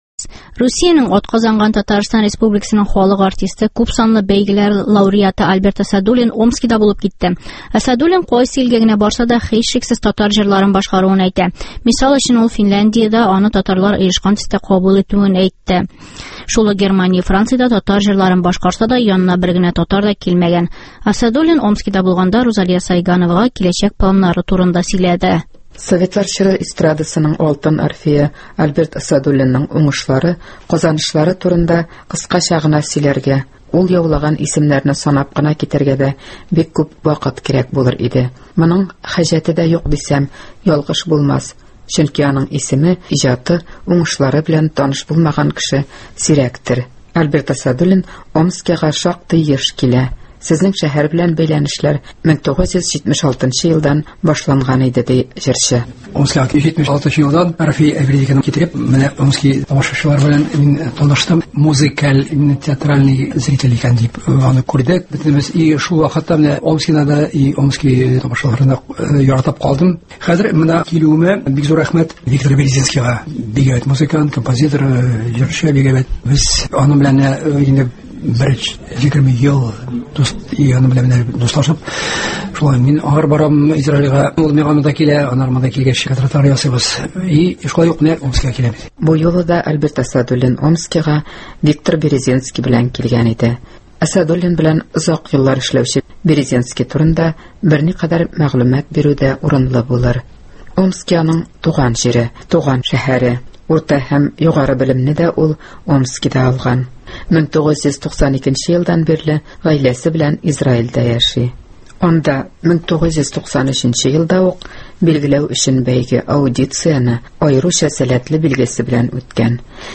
Омскида Альберт Әсәдуллин концерты үтте